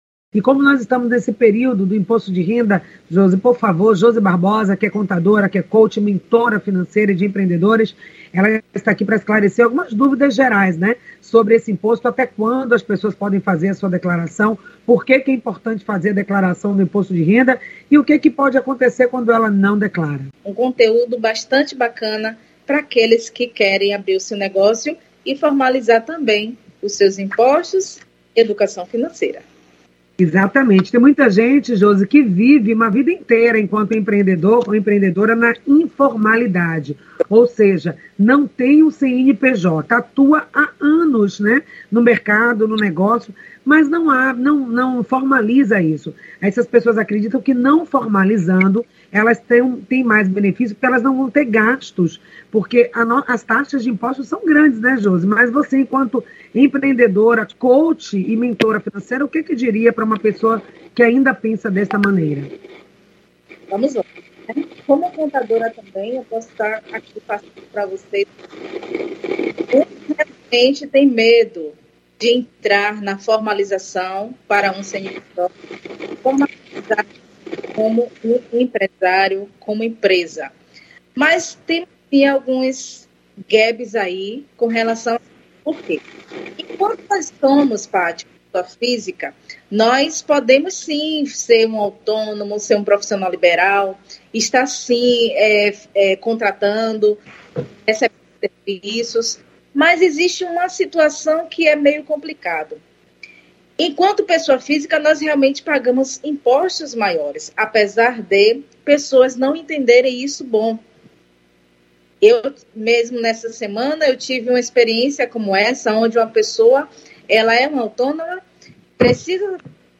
O programa Em Sintonia acontece de segunda a Sexta- feira, das 9 às 10h, pela Rádio Excelsior AM 840.